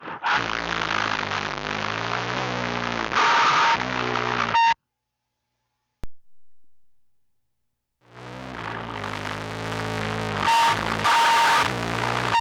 I produce noise music sometimes, and When recording directly from my mic to my PC in Audacity, the sound is thin, fuzzy and the bitrate sounds like it's at like 8kbps.
If I record through stereo mix it sounds fine.
[I posted audio showing you a before/after using stereo mix] Attachments Test.mp3 Test.mp3 487.5 KB · Views: 104